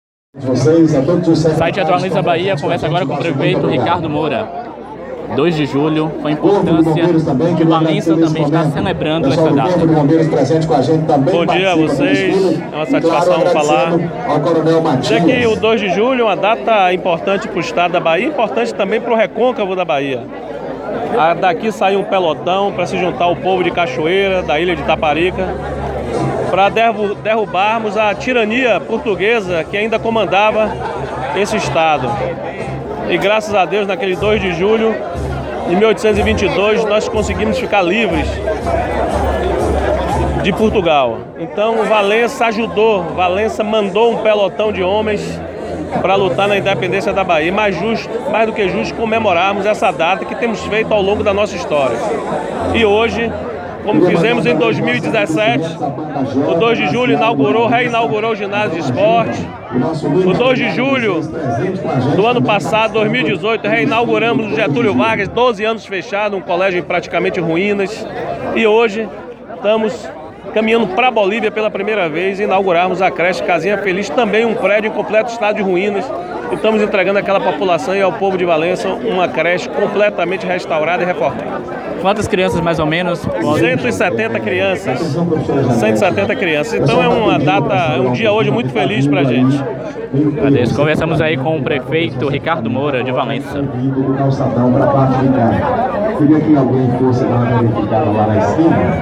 O prefeito da cidade, Ricardo Moura, esteve presente.